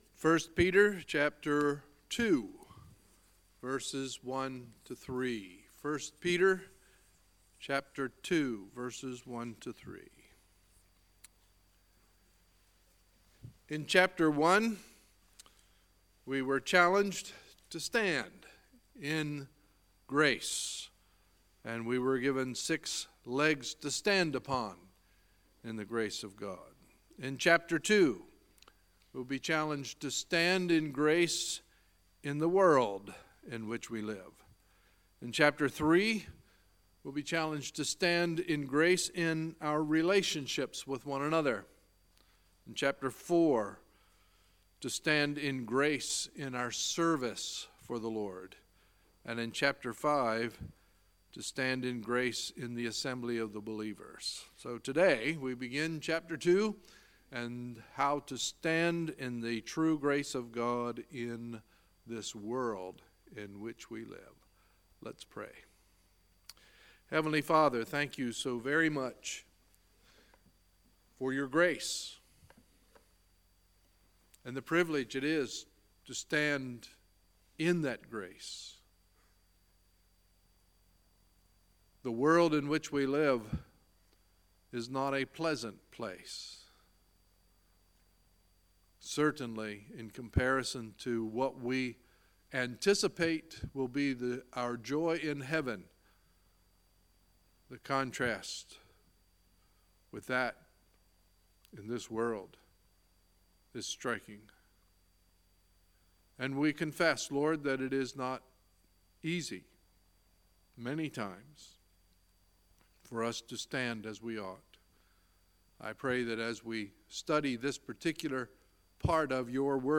Sunday, April 8, 2018 – Sunday Morning Service